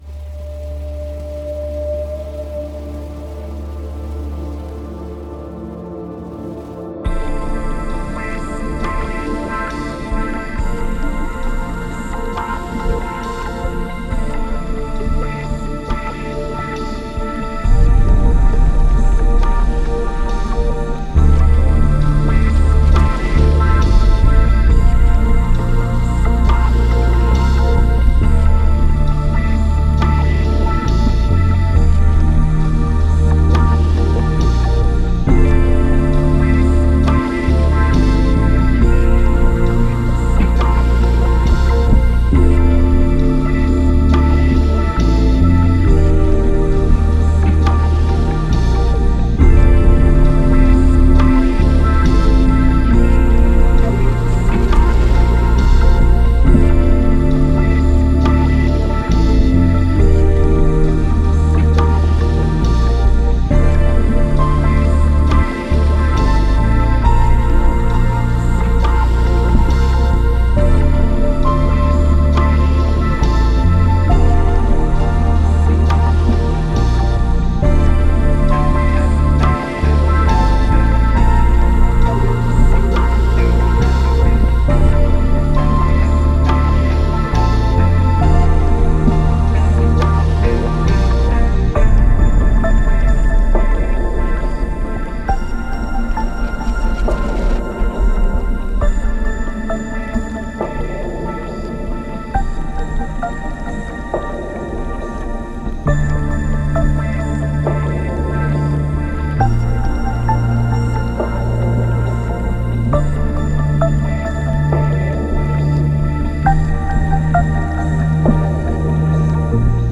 Genre: easylistening, newage.